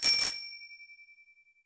bell.ogg